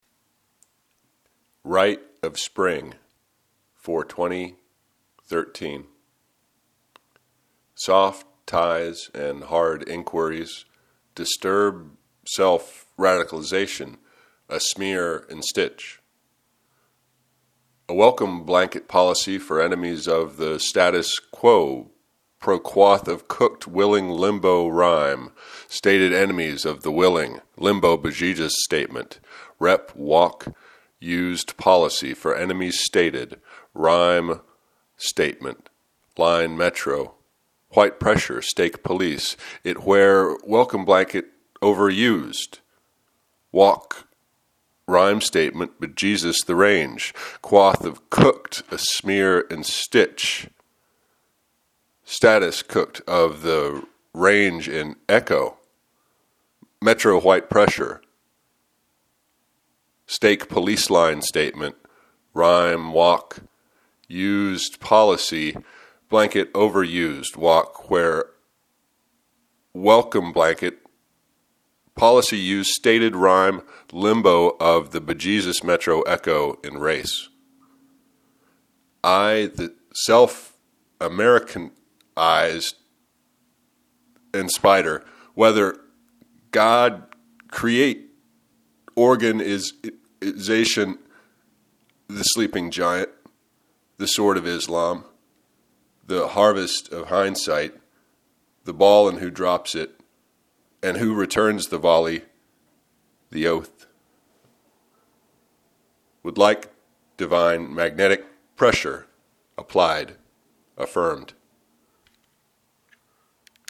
Rite of Spring, Reading 1: